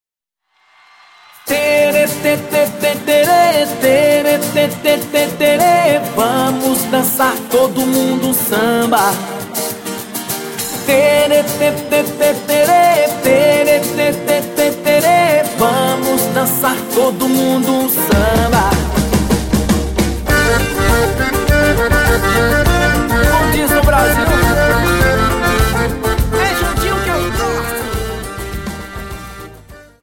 Dance: Samba